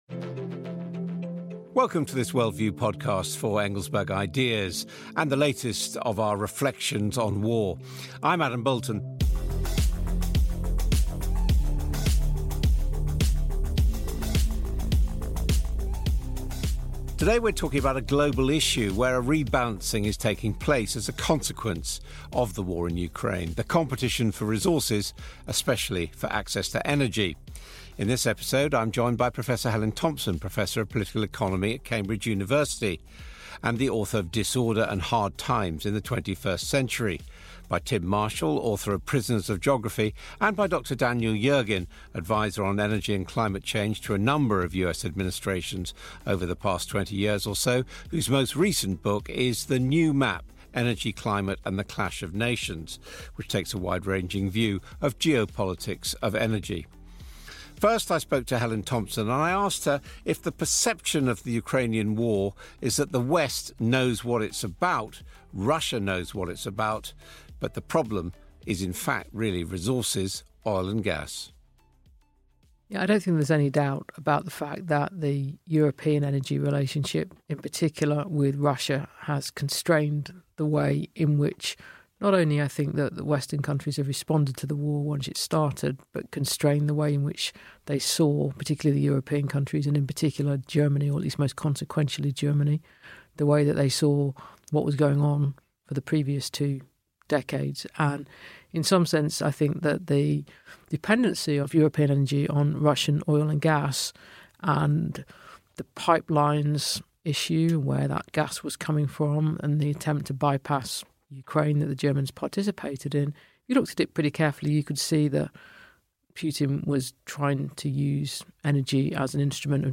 In this episode of Worldview, Adam Boulton is joined by Professor Helen Thompson, Tim Marshall and Daniel Yergin to discuss the global energy market.